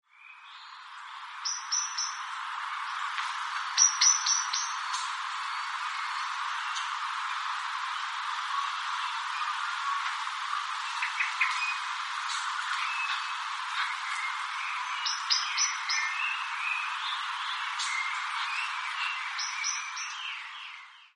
Common Blackbird -Turdus merula
Call 2: Pink-pink call
Com_Blackbird_2_pink.mp3